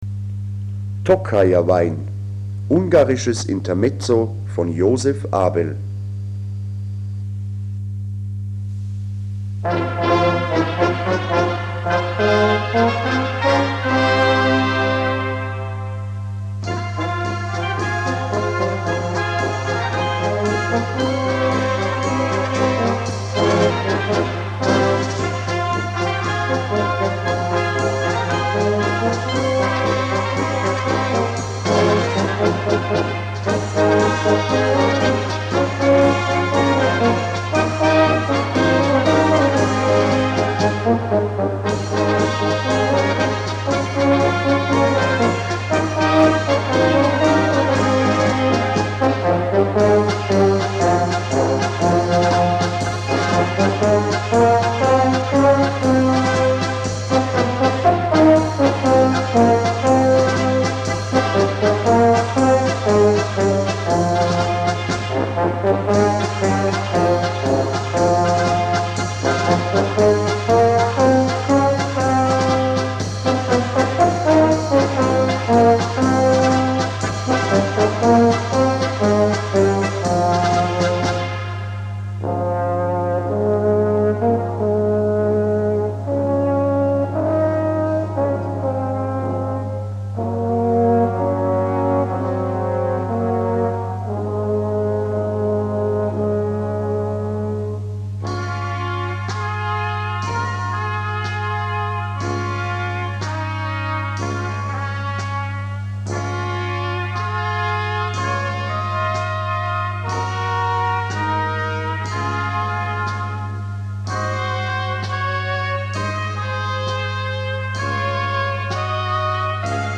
Gattung: Intermezzo
Besetzung: Blasorchester